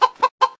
better_fauna_chicken_idle.ogg